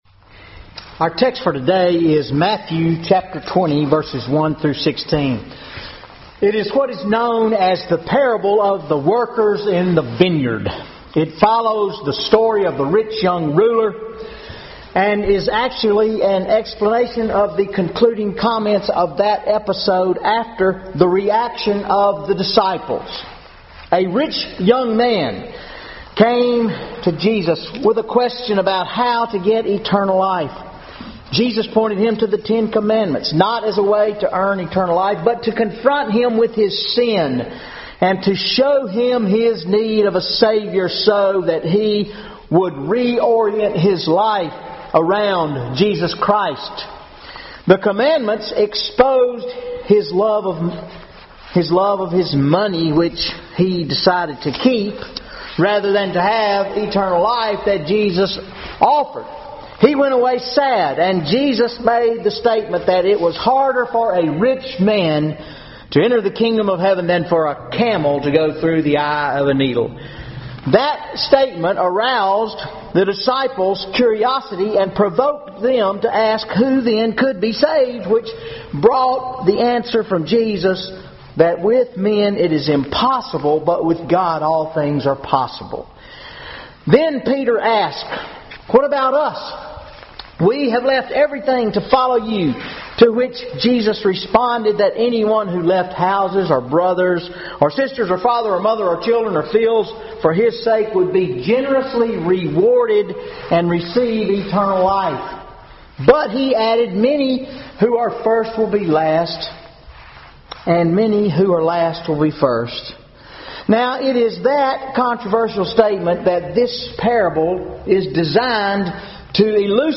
Sunday Sermon November 16, 2014 Matthew 20:1-16 Is God Unfair with His Grace?